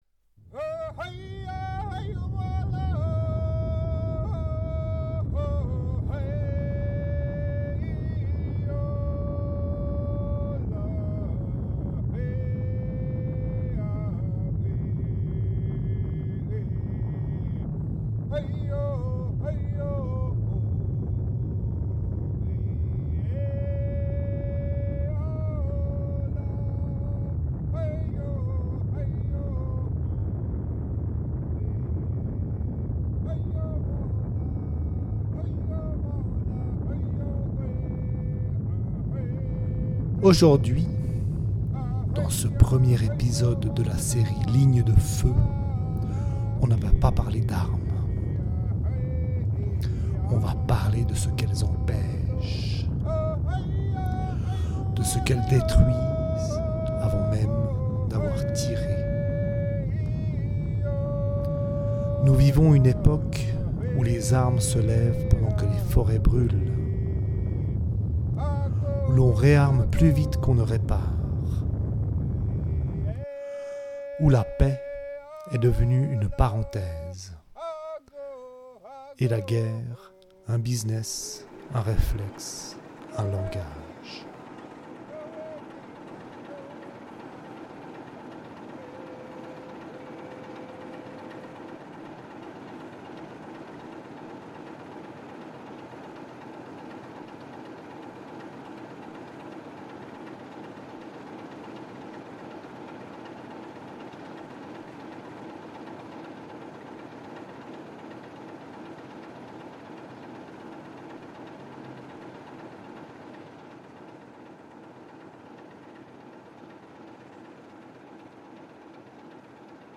Bientôt en ligne : des capsules audio mêlant écologie radicale, critique culturelle et performances sonores.